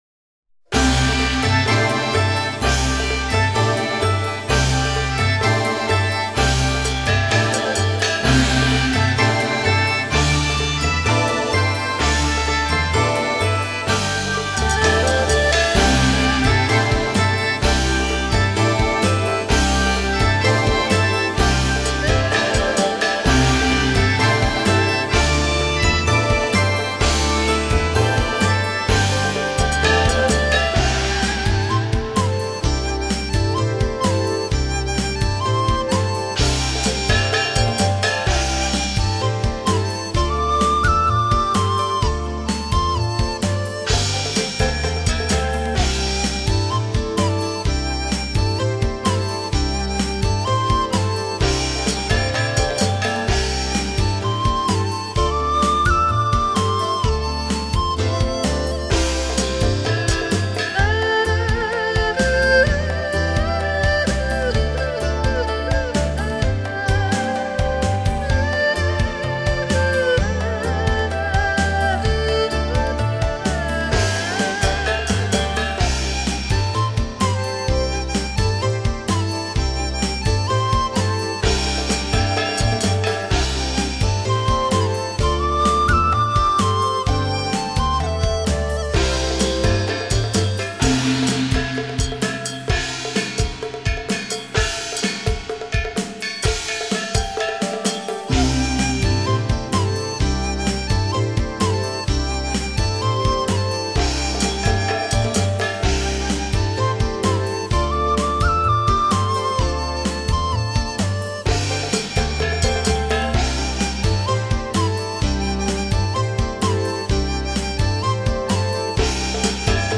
滿心祝福的喜悅！在歡樂的節慶裡，胡琴、把烏、笙、笛、鑼、